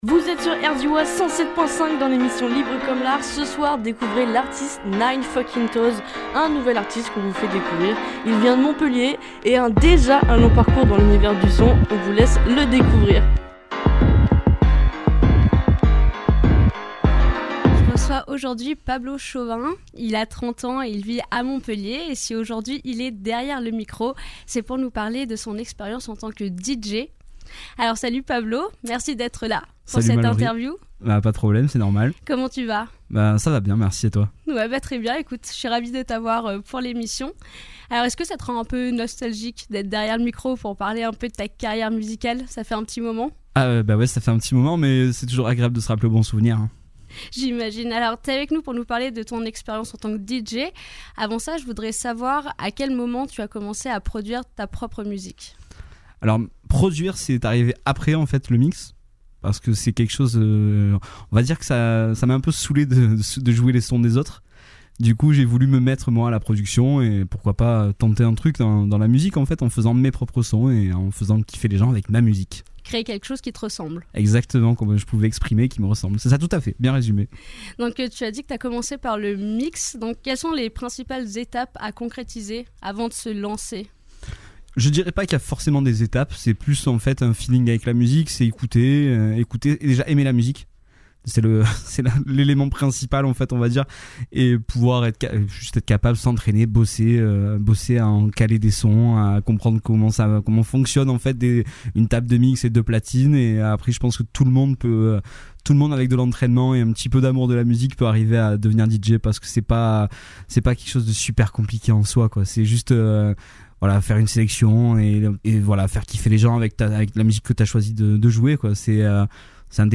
Deux de ces titres se trouve à la fin de l’interview :